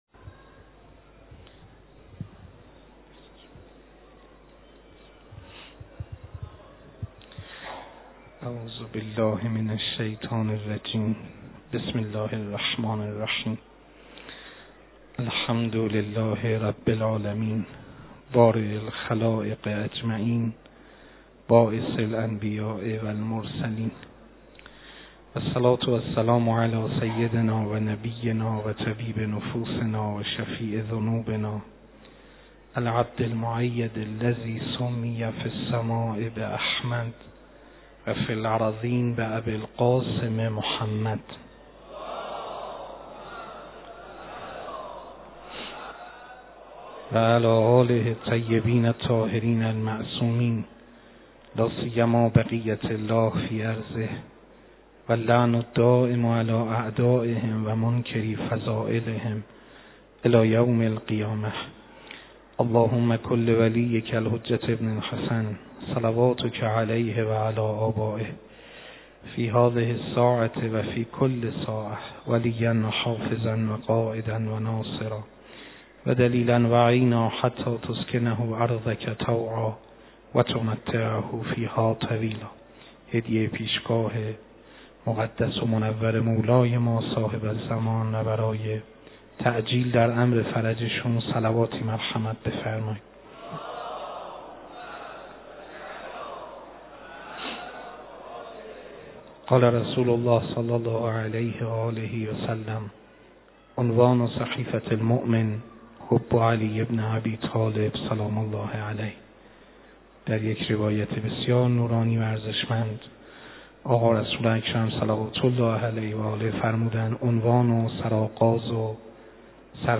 سخنرانی و مداحی